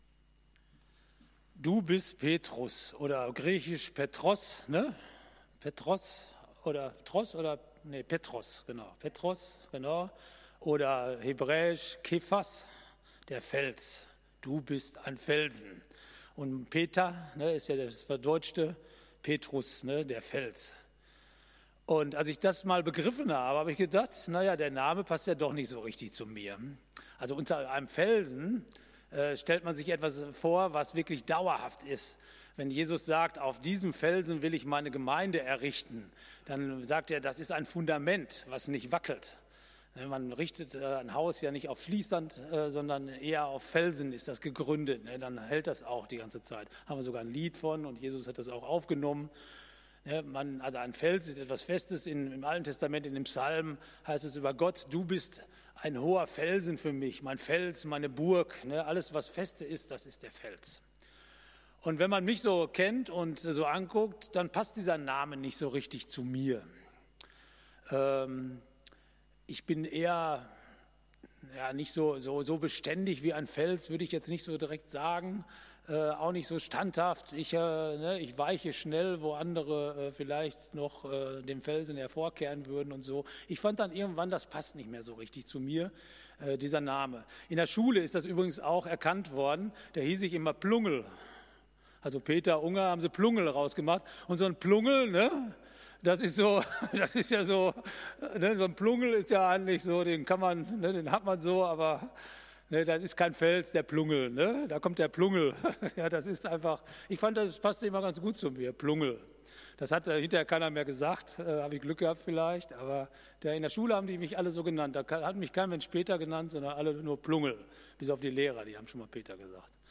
Gottesdienst
Apr. 11, 2021 | Predigten | 0 Kommentare